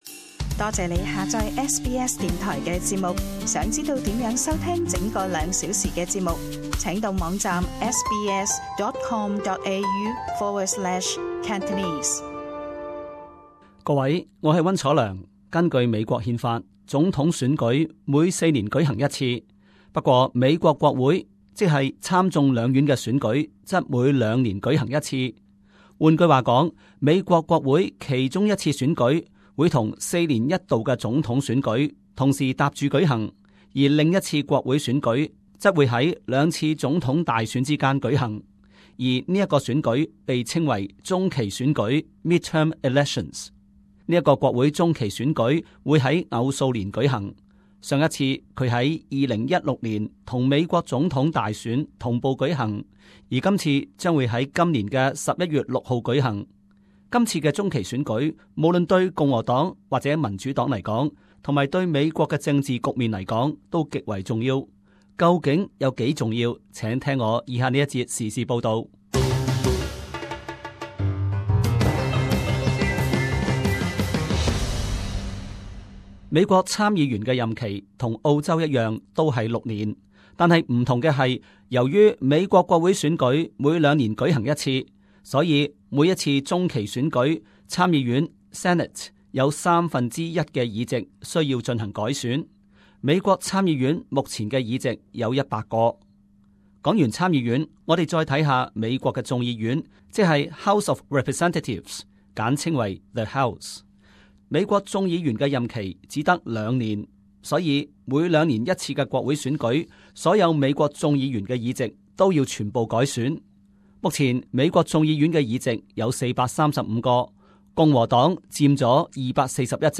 【時事報導】 美國中期大選影響深遠